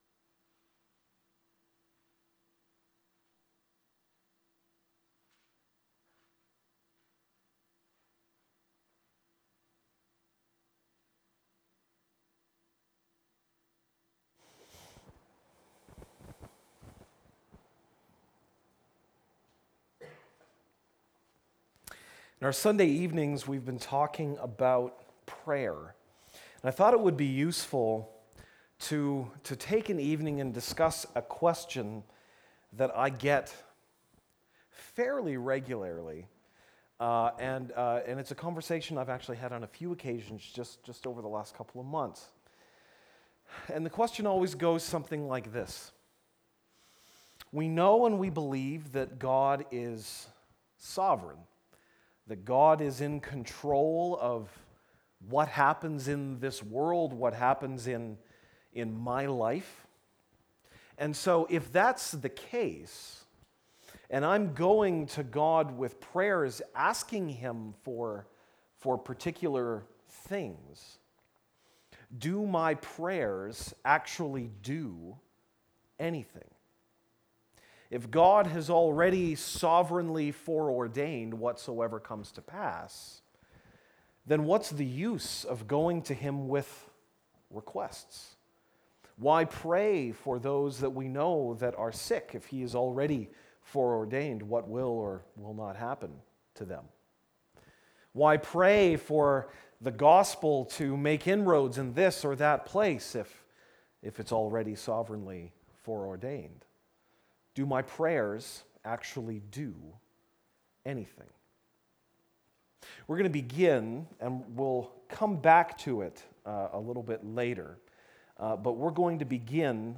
Series: Evening service sermons 2017